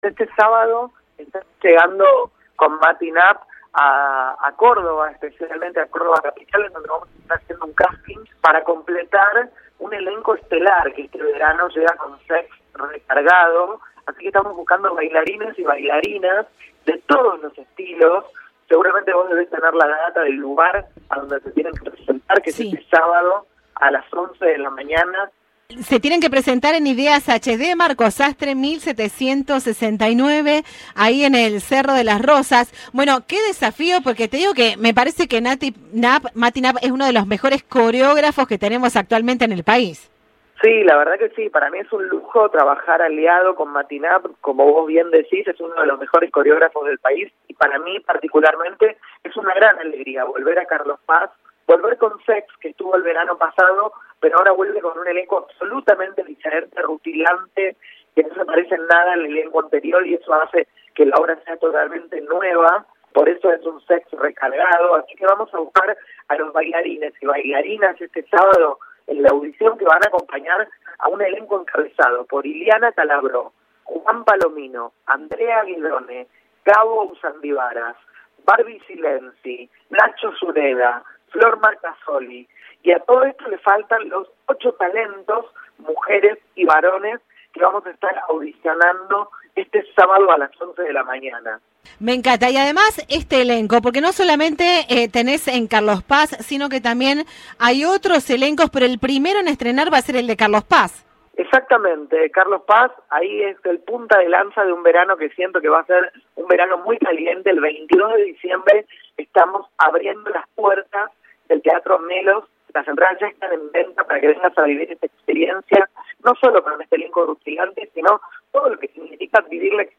Entrevista de Viva la Radio.